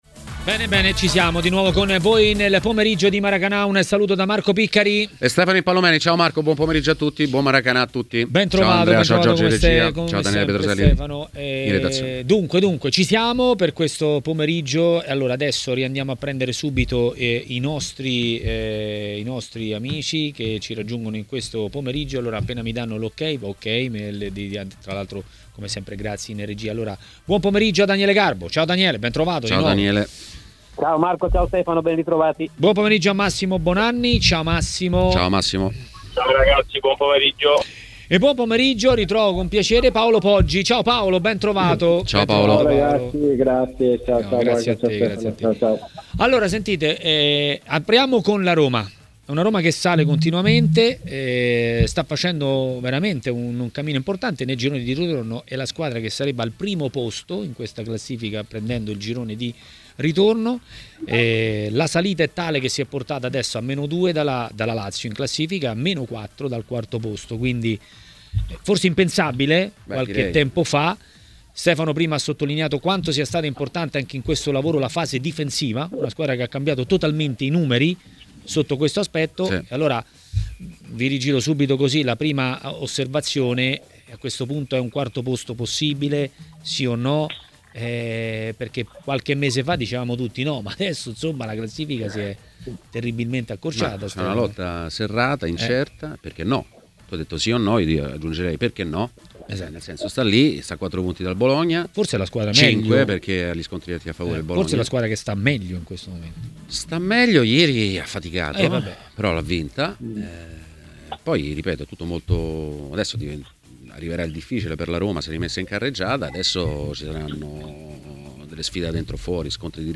Ospite di TMW Radio, durante Maracanà, è stato l'ex calciatore Paolo Poggi.